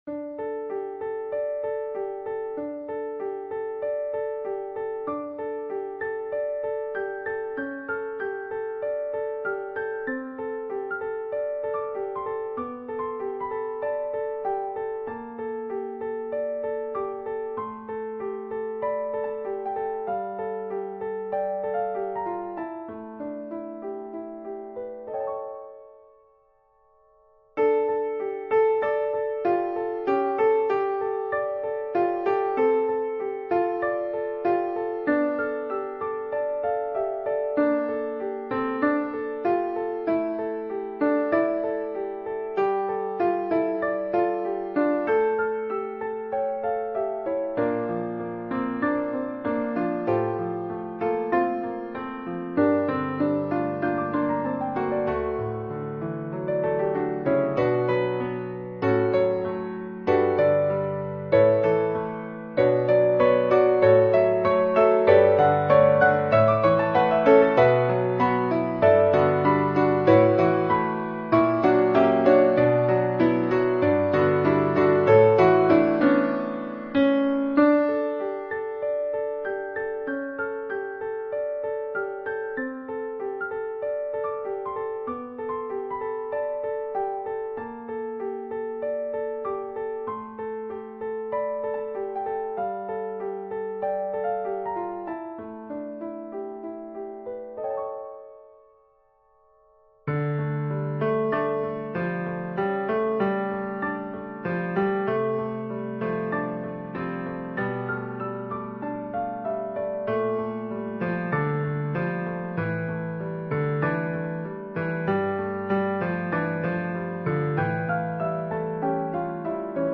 SATB mixed choir and piano
世俗音樂
段落中可見以兩音為一動機（如下方譜例），並將演唱音域持續向上擴增，表達角色於情感悸動之時的語氣。